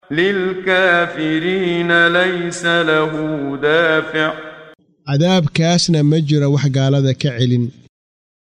Waa Akhrin Codeed Af Soomaali ah ee Macaanida Suuradda A-Macaarij ( Wadooyinka samada ) oo u kala Qaybsan Aayado ahaan ayna la Socoto Akhrinta Qaariga Sheekh Muxammad Siddiiq Al-Manshaawi.